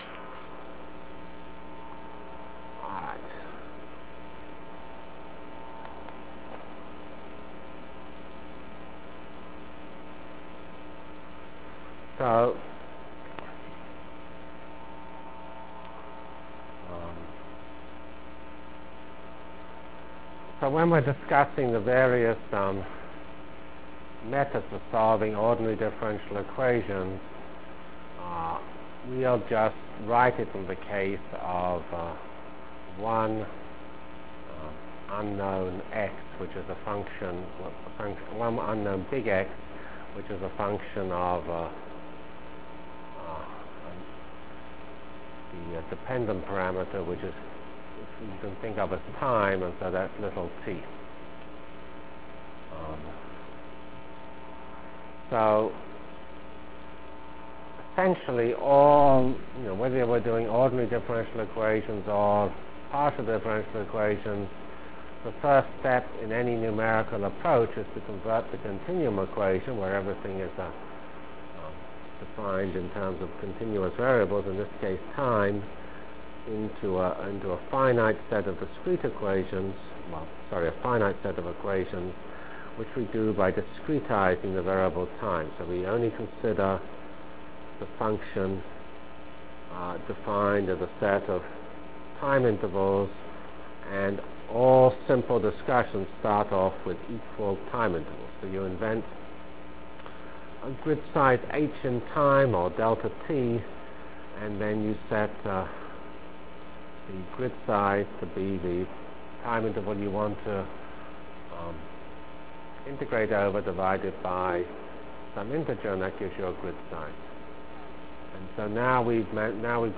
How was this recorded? From CPS615-Discussion of Ordinary Differential Equations and Start of Parallel N-Body Algorithm Delivered Lectures of CPS615 Basic Simulation Track for Computational Science -- 10 October 96.